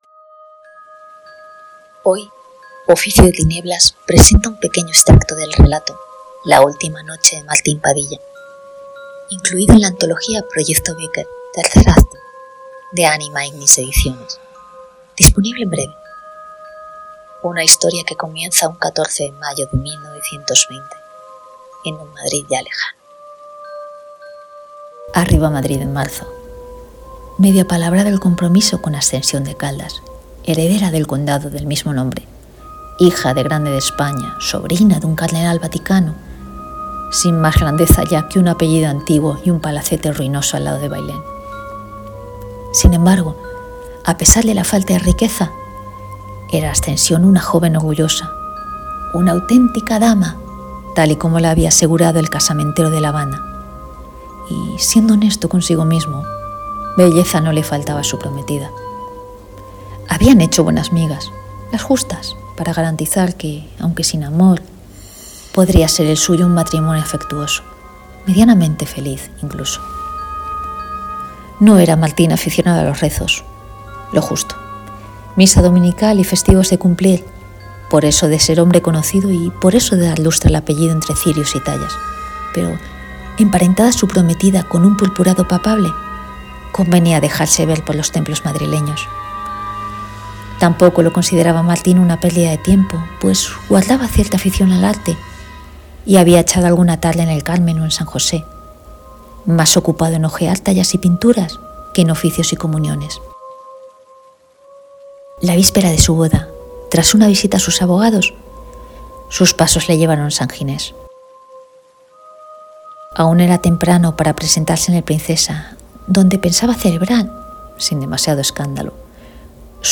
la-ultima-noche-de-martin-padilla-con-musica.mp3